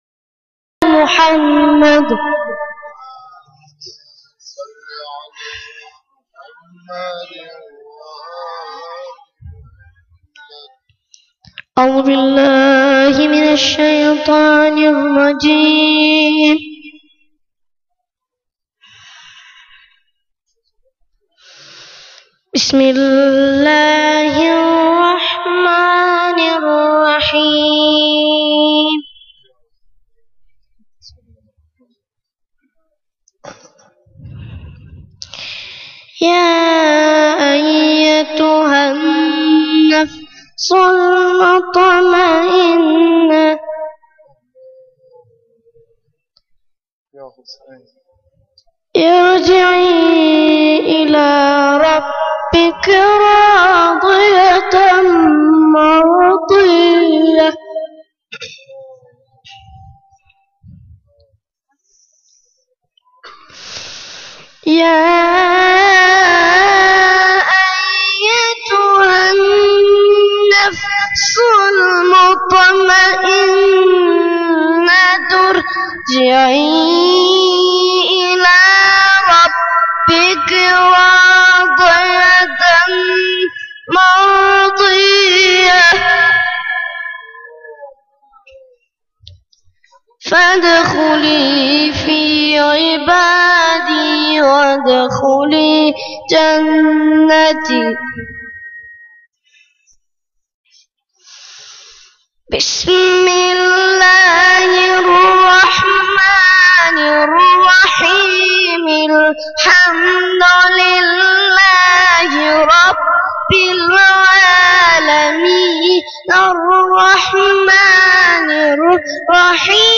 سخنرانی11.1.wma